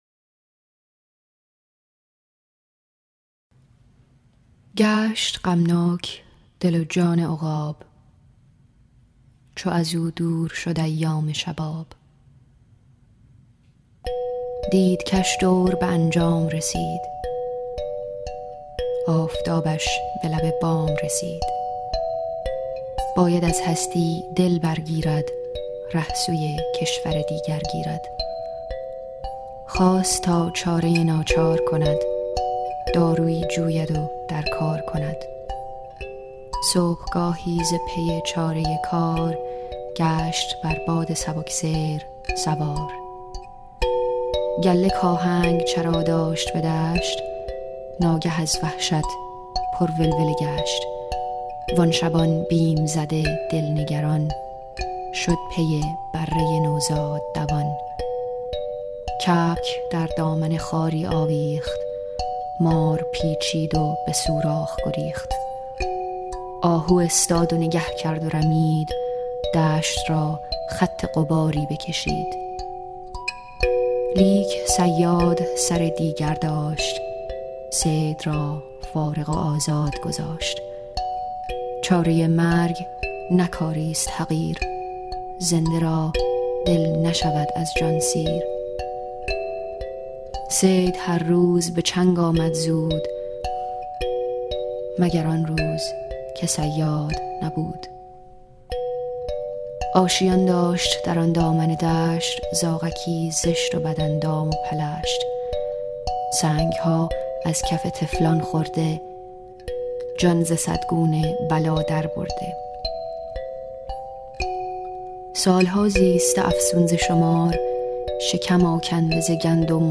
دانلود آهنگ لری